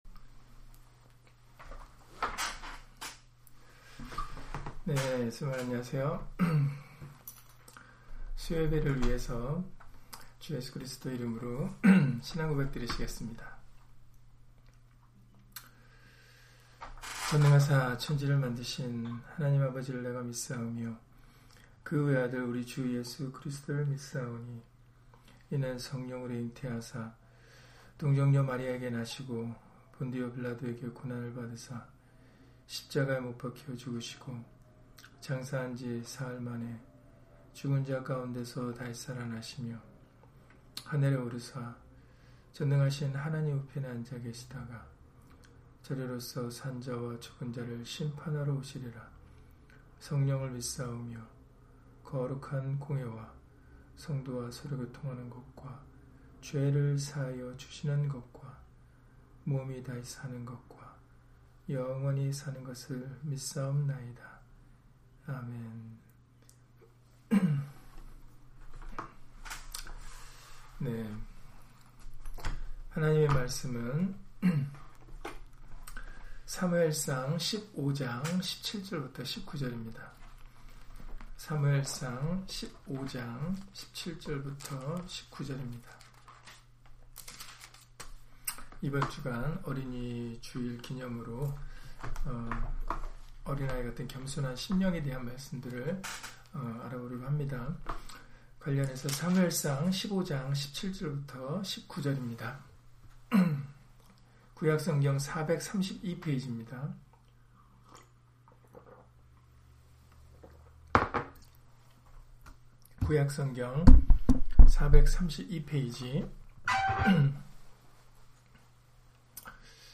사무엘상 15장 17-19절 [스스로 작게 여기는 자가 되자] - 주일/수요예배 설교 - 주 예수 그리스도 이름 예배당